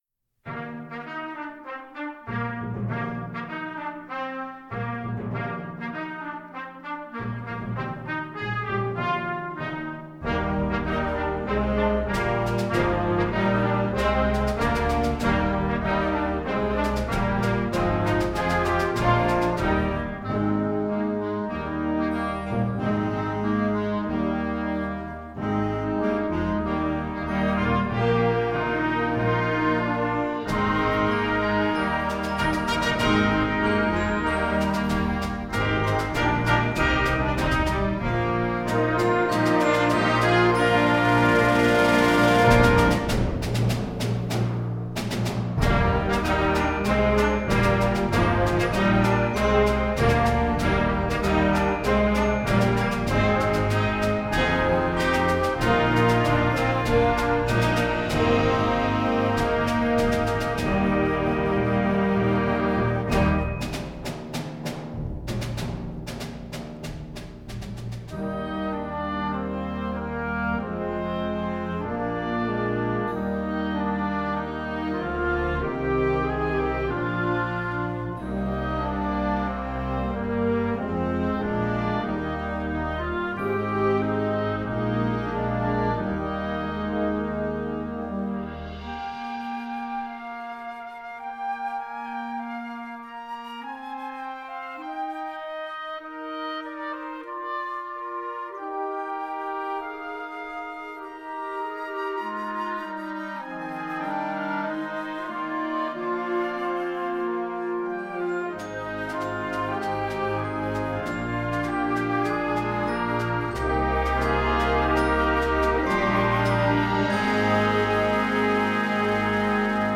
Gattung: Konzertwerk für Jugendblasorchester
Besetzung: Blasorchester
üppige Klänge und reife musikalische Momente zu schaffen.